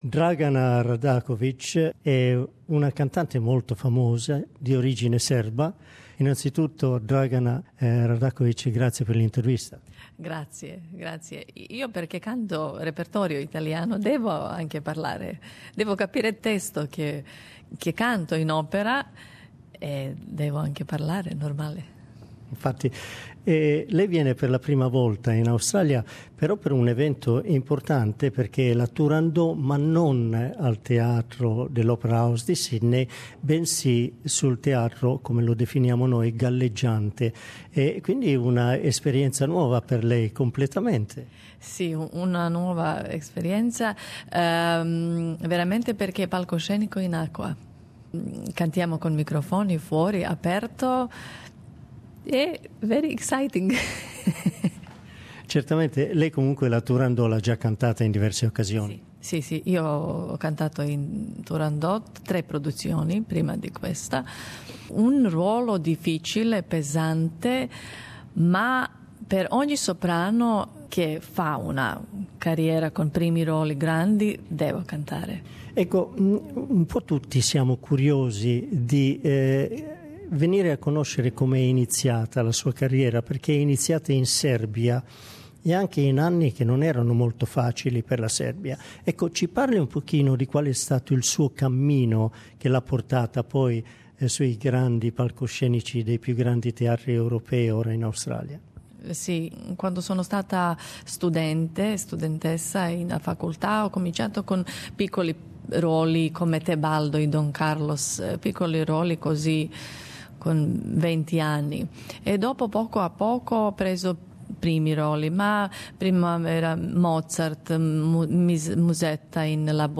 Our interview.